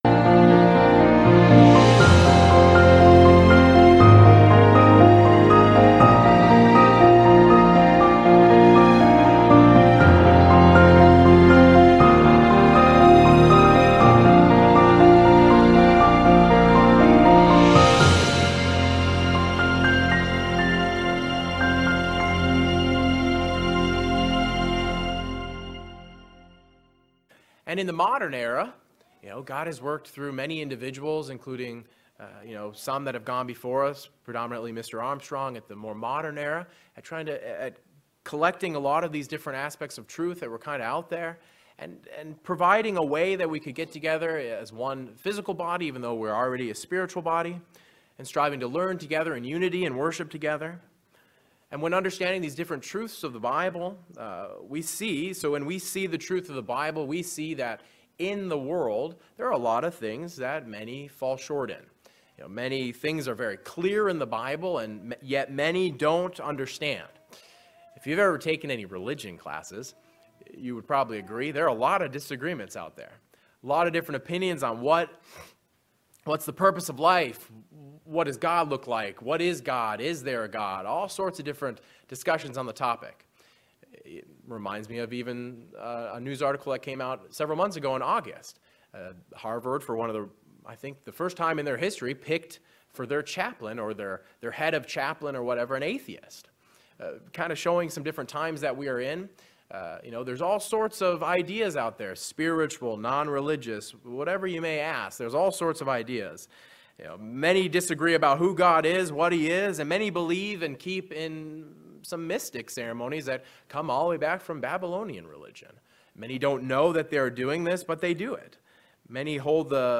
In the fourth chapter of John, we see an interesting discussion between a Samaritan woman and Jesus where Jesus states that true worshippers worship God in spirit and truth. This sermon looks into the meaning behind part of that statement, worshipping God in spirit.